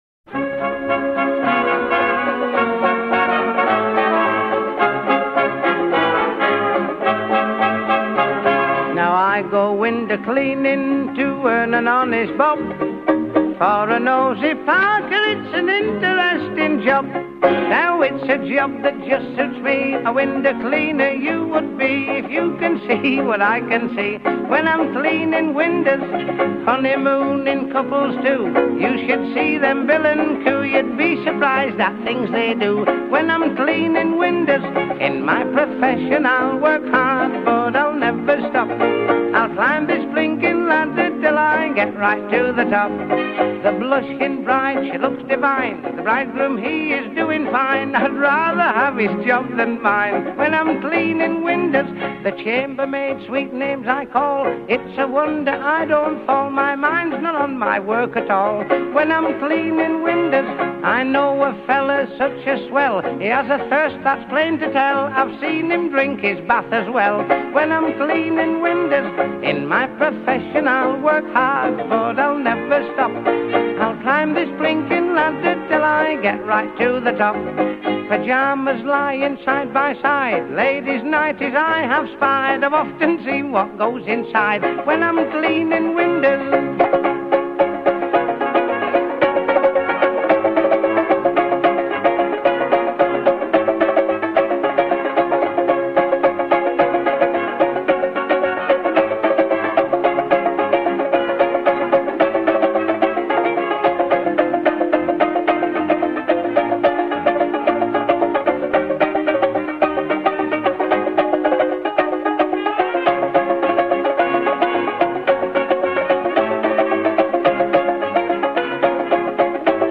voice and ukulele
A A verse 0:17 4 vocal solo with refrain b
B A verse 1:27 4 ukulele chords with band
Music Hall